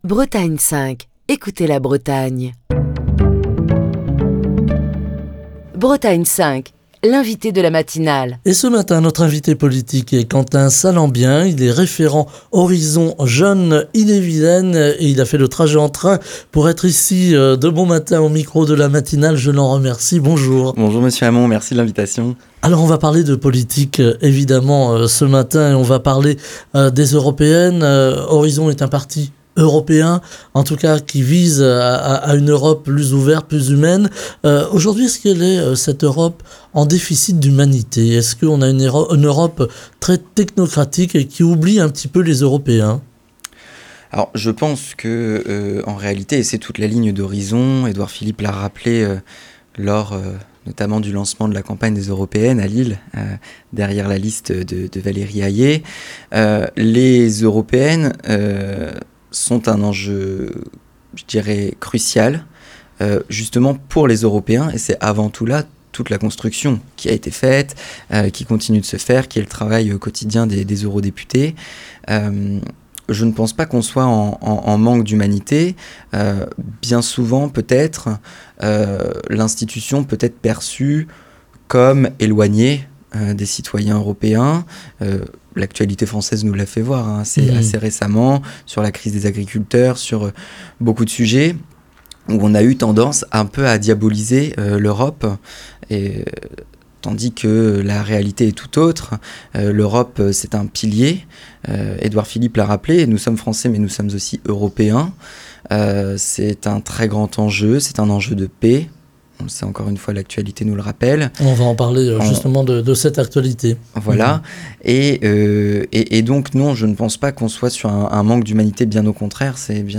invité politique de la matinale de Bretagne 5